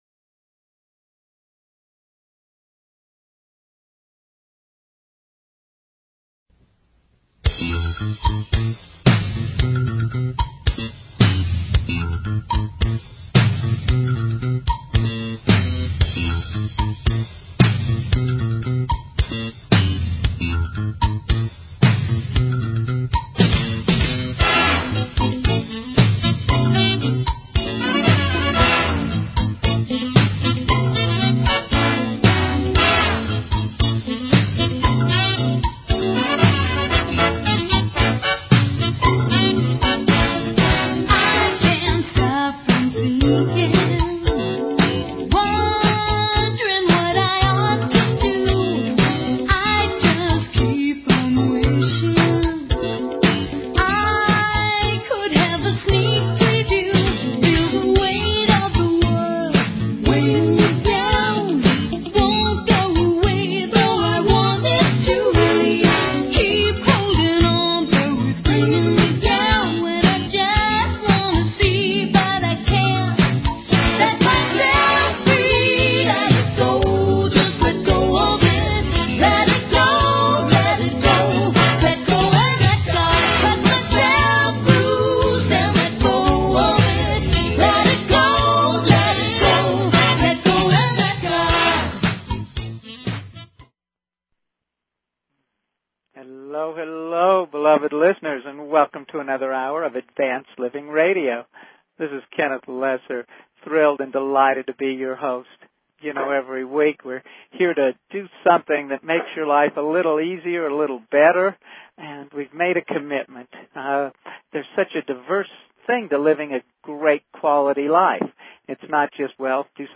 Show Headline Advanced_Living Show Sub Headline Courtesy of BBS Radio On